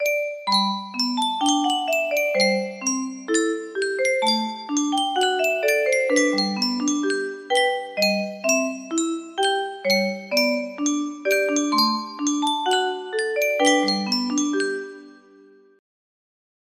Clone of Yunsheng Custom Tune Spieluhr - Dies Bildnis music box melody